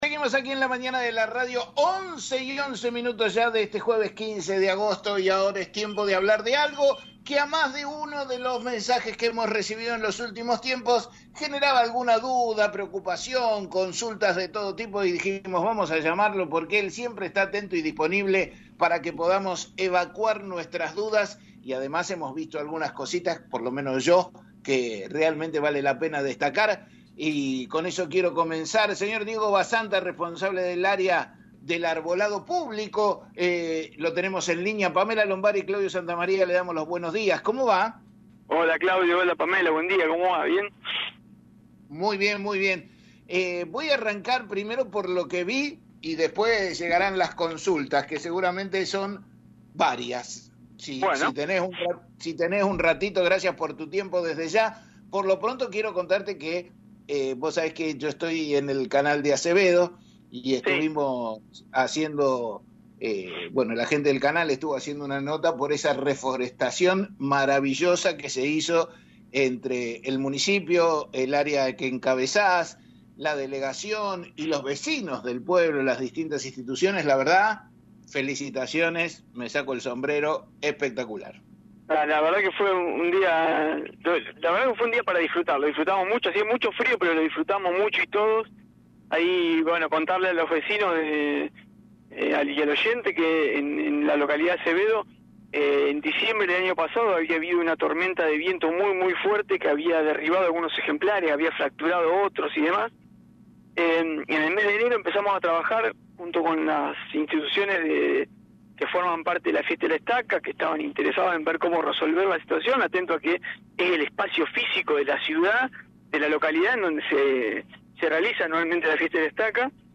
En una reciente entrevista